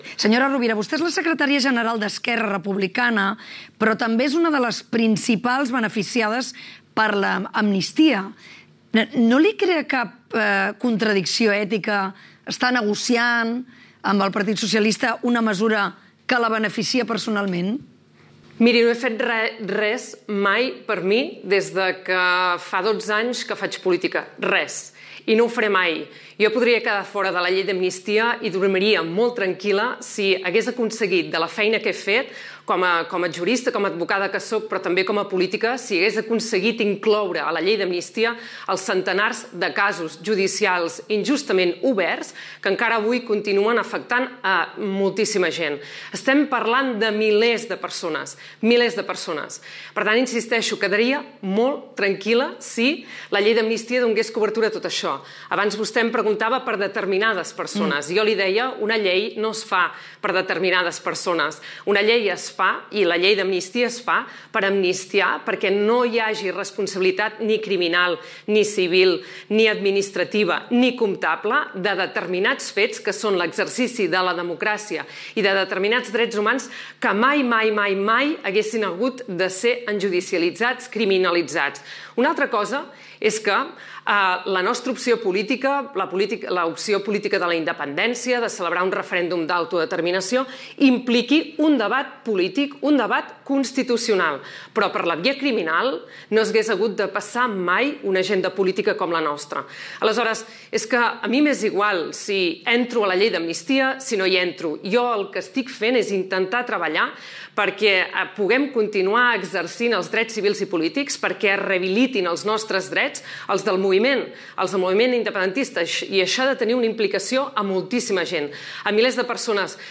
Info-entreteniment
Fragment enregistrat en directe al compte de RTVE Catalunya a la xarxa social X (Twitter)